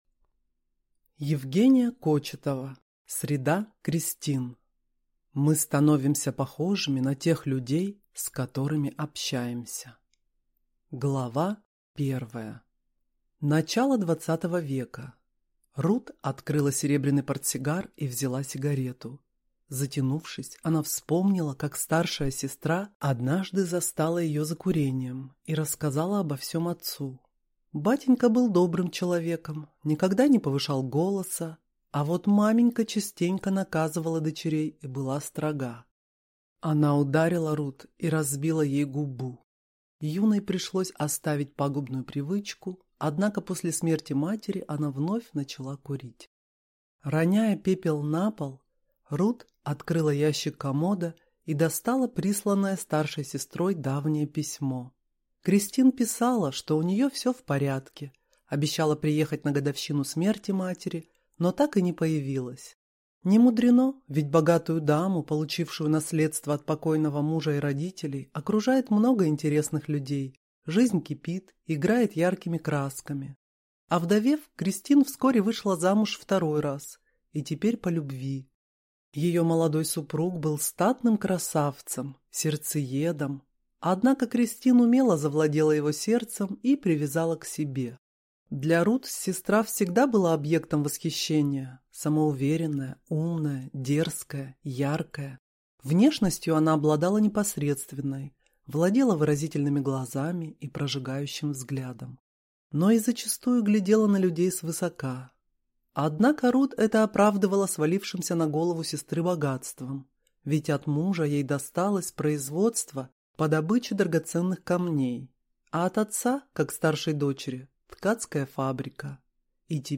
Аудиокнига Среда Кристин | Библиотека аудиокниг
Прослушать и бесплатно скачать фрагмент аудиокниги